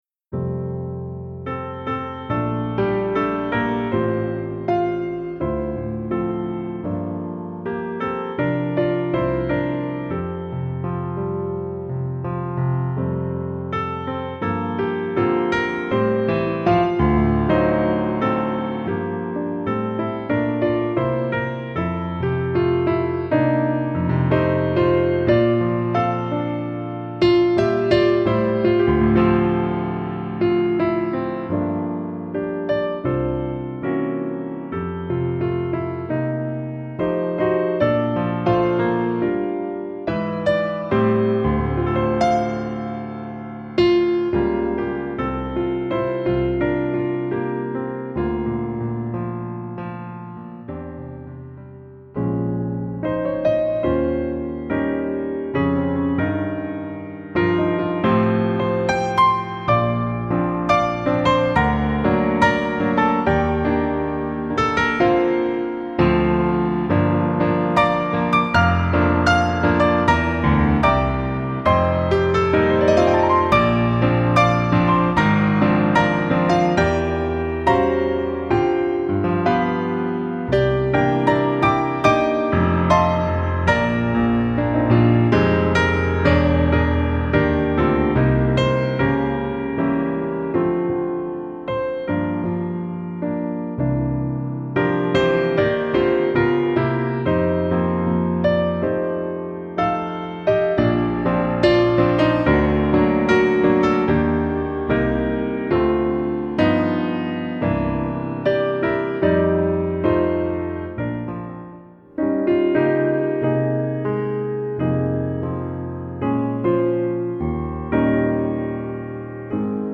Recueil pour Piano